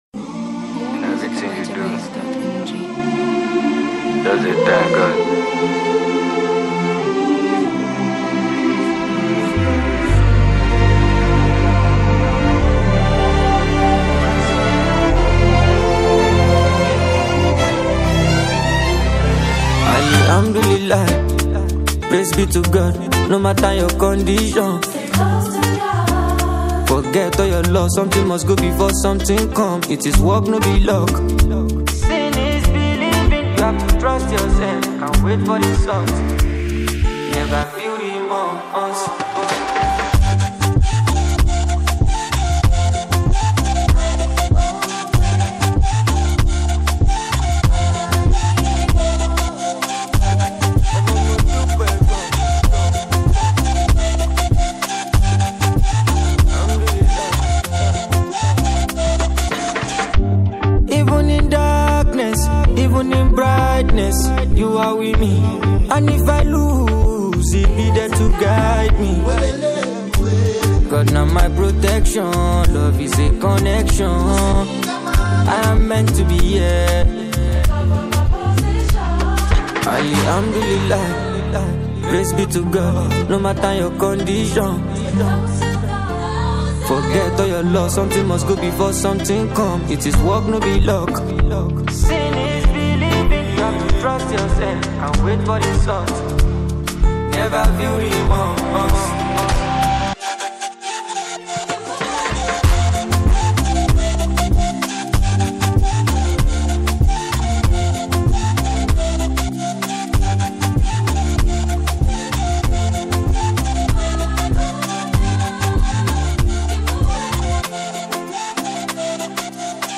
spiritually charged Afrobeats-EDM fusion single
signature Fuji-infused, emotive Afrobeats style
high-energy, hypnotic electronic production